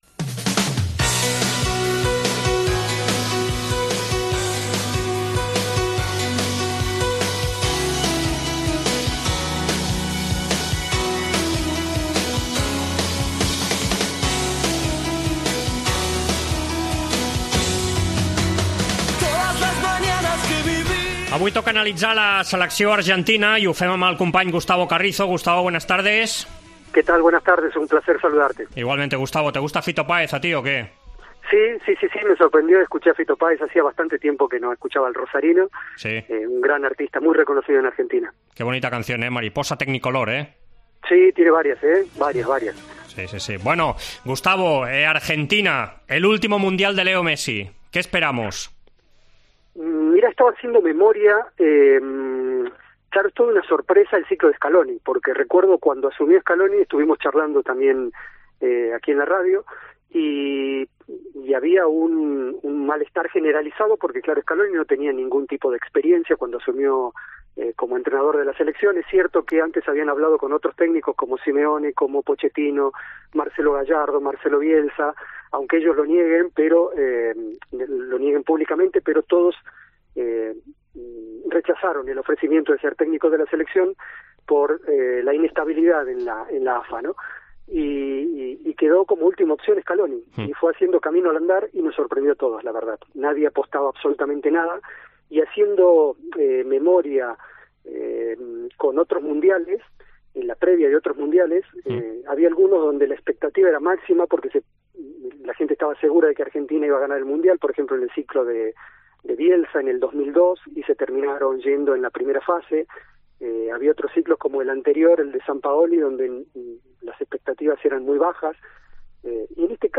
Parlem amb el periodista argentí